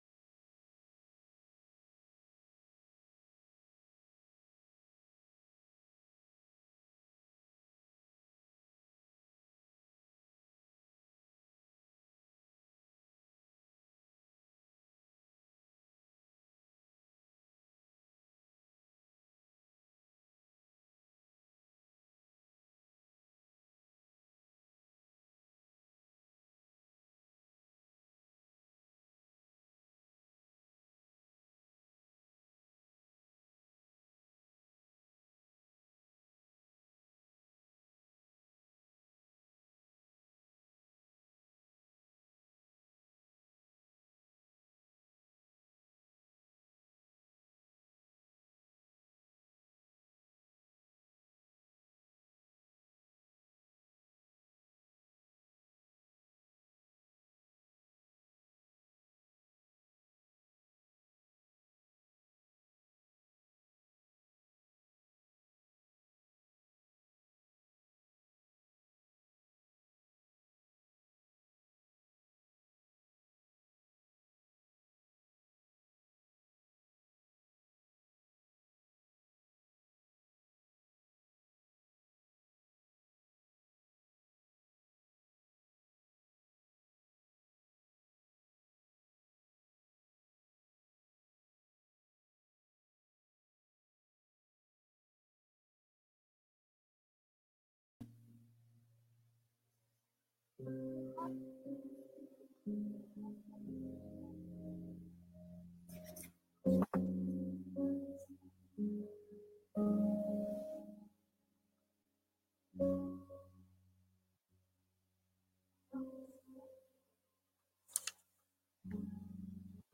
non-scripted, live, non-profit, broadcast